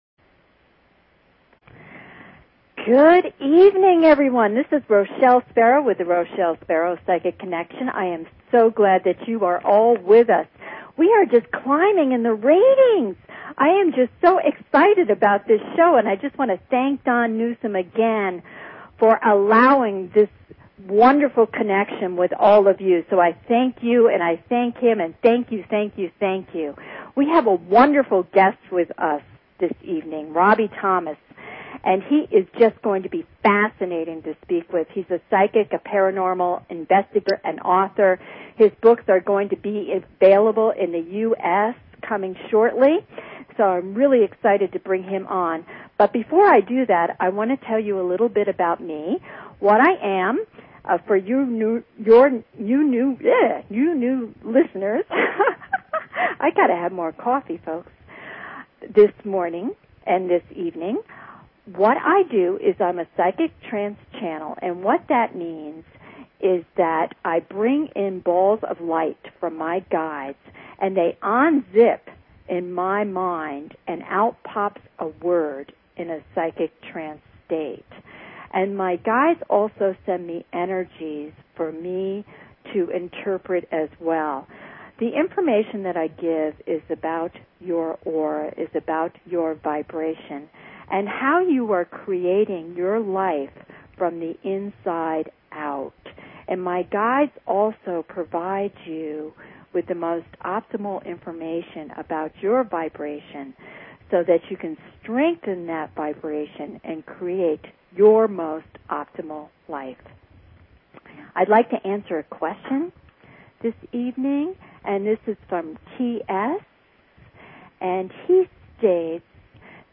Talk Show Episode, Audio Podcast, Psychic_Connection and Courtesy of BBS Radio on , show guests , about , categorized as